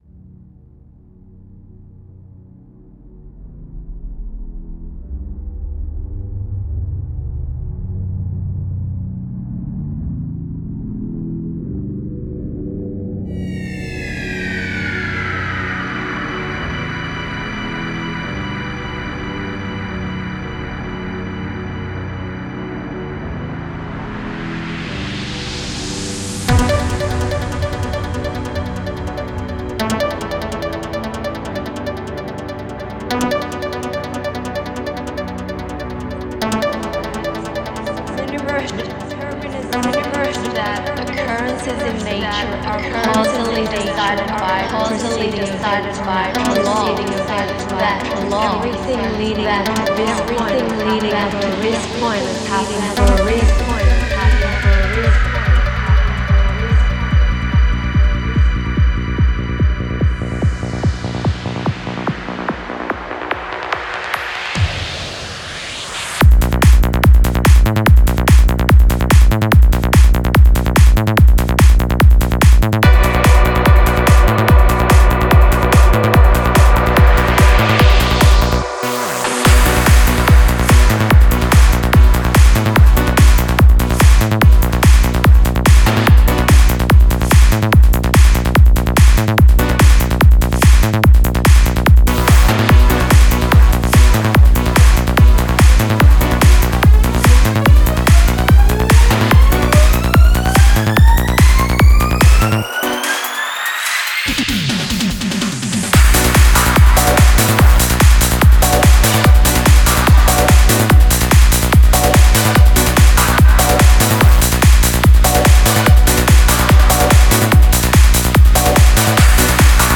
Style: FullOn
Quality: avg. 252kbps / Joint Stereo